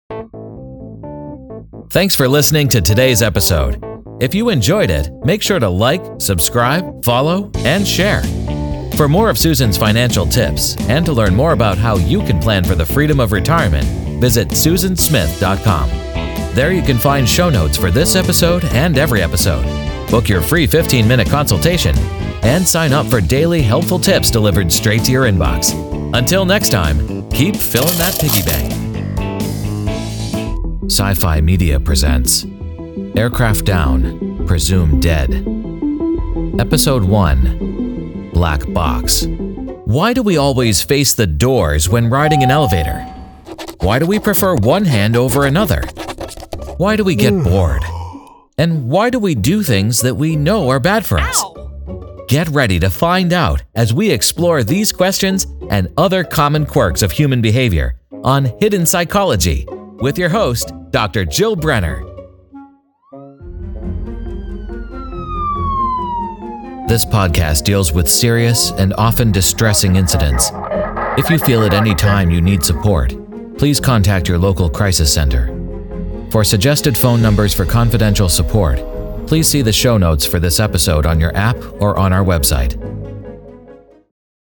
Male
English (North American)
My voice has the just-right combination of calming authority and genuine warmth.
Podcast Intro/Outro Demo 1